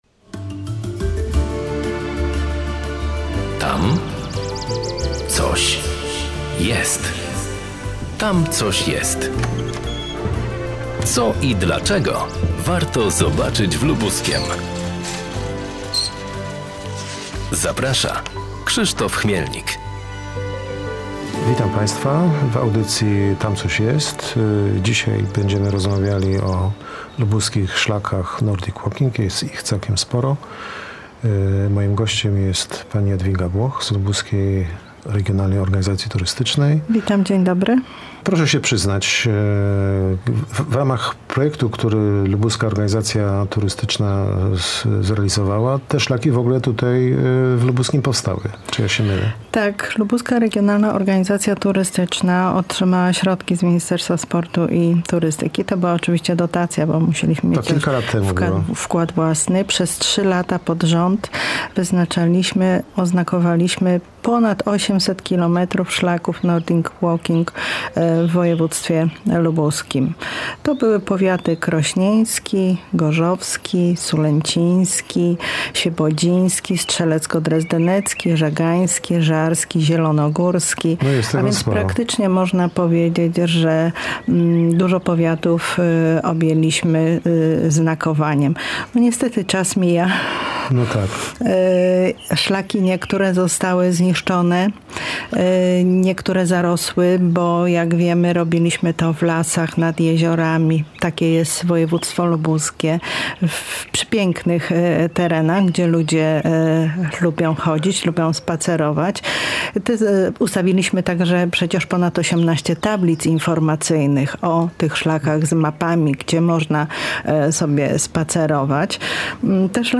W tym wydaniu audycji rozmawiamy o lubuskich szlakach nordic walking, których w regionie jest coraz więcej i które zyskują na popularności.